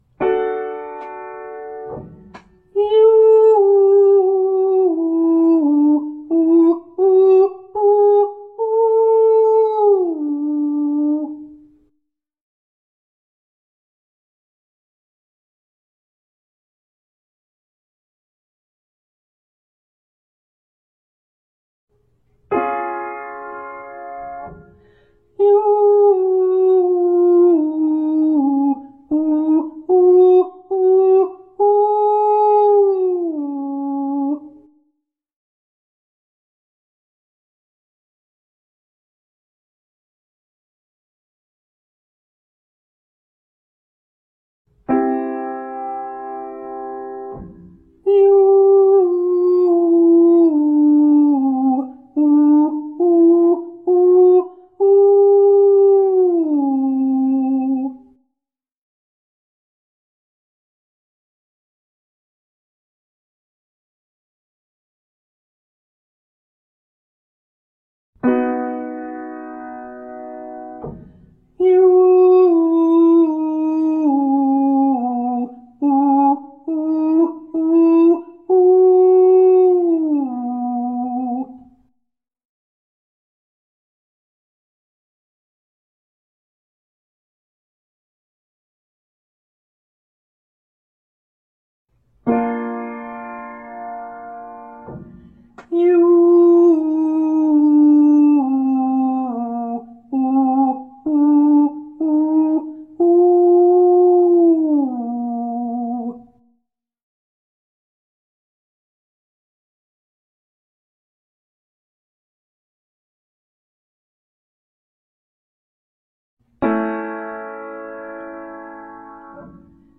Singing Lessons and Vocal Coaching
Legato Staccato Cry on /yoo/ (D)
Vft-1114-Legato-Staccato-Cry-Yoo-Descending.mp3